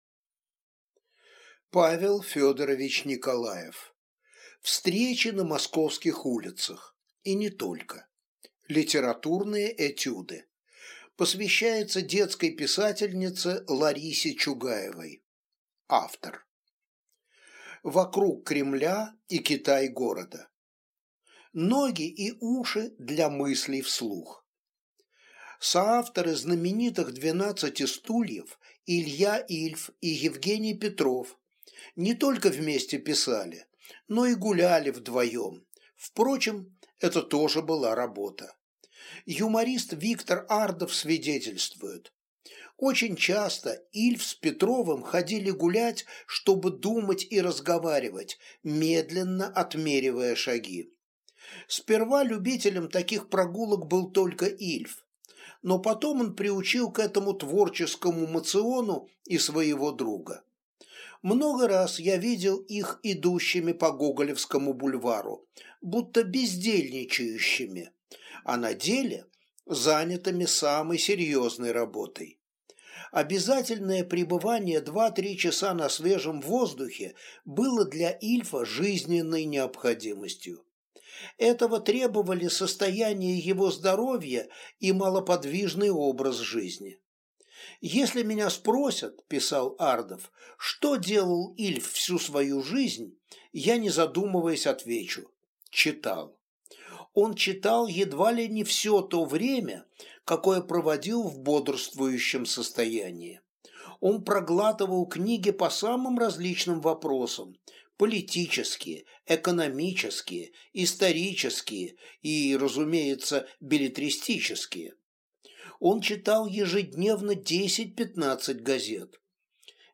Аудиокнига Встречи на московских улицах | Библиотека аудиокниг